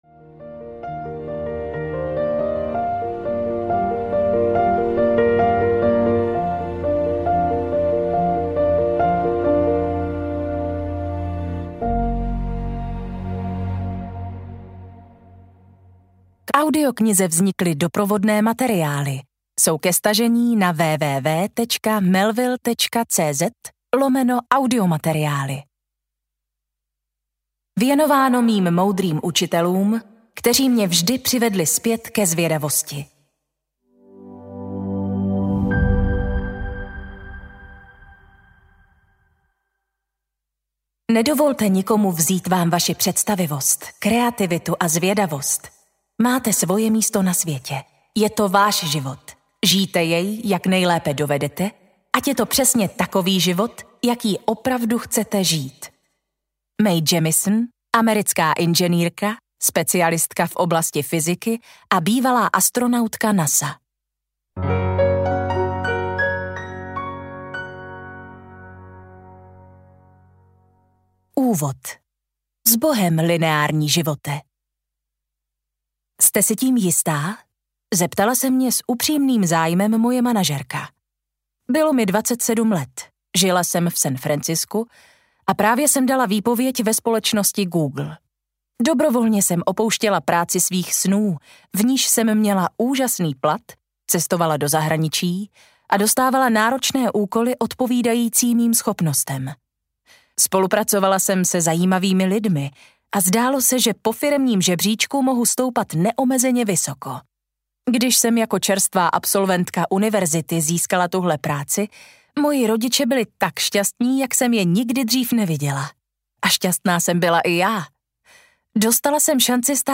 Malé experimenty audiokniha
Ukázka z knihy